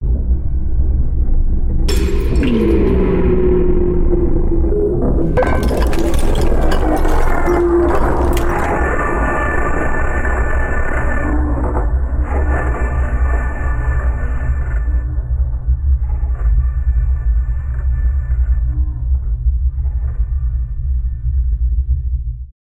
Звуки проклятия
От шепота потусторонних сущностей до зловещих завываний – каждый звук перенесёт вас в мир, где реальность теряет границы.
4. Фон